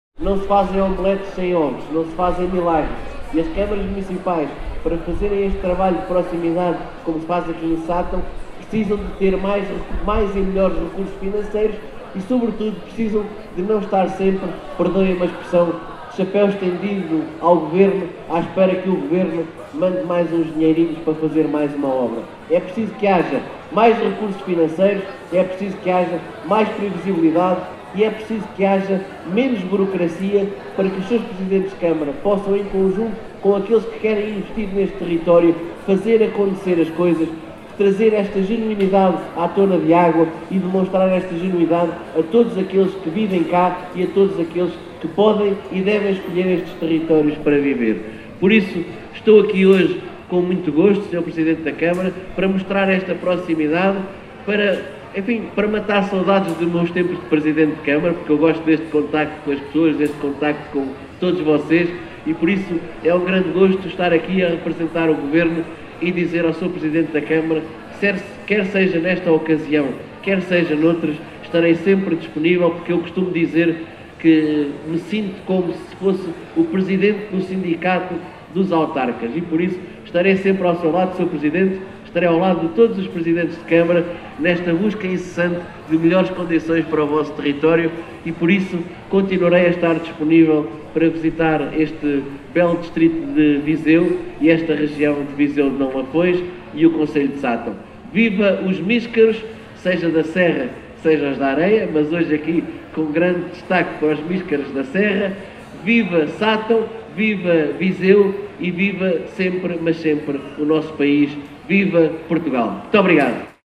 Numa organização do Município de Sátão, este domingo, 30 de novembro, a XVIII Feira do Míscaro que decorreu no Largo de São Bernardo, voltou a reunir muitos visitantes, oriundos do concelho, da região e do país.
Silvério Regalado, Secretário de Estado da Administração Local e Ordenamento do Território, referiu a importância da existência de maiores recursos económicos, com menos burocracia, para que as Câmaras tenham mais condições para promover os seus territórios.